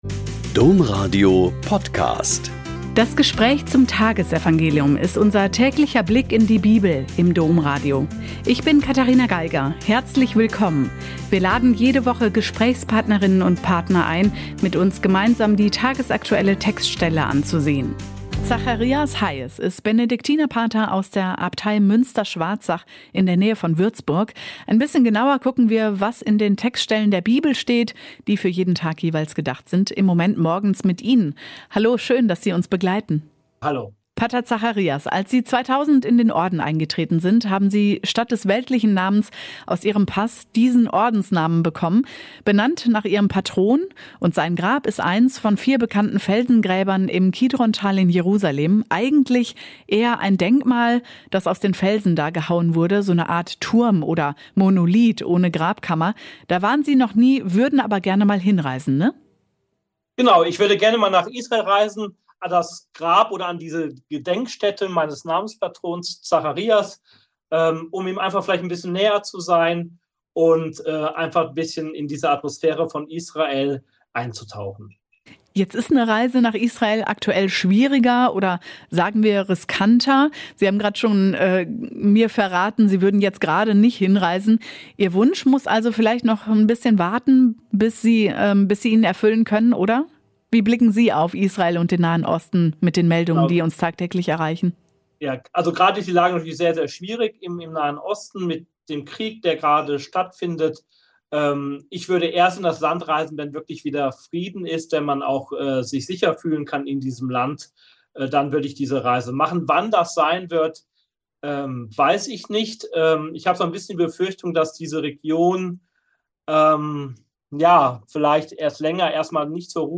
Lk 12,39-48 - Gespräch